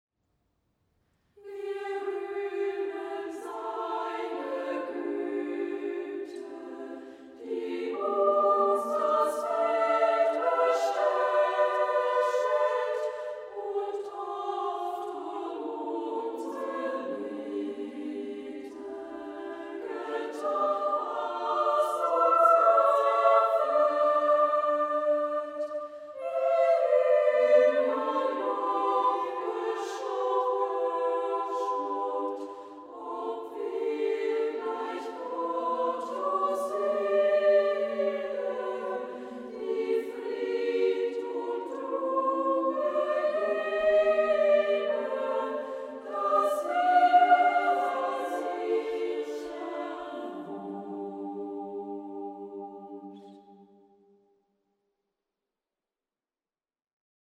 Werke für Frauen- und Kinderchor und Sololieder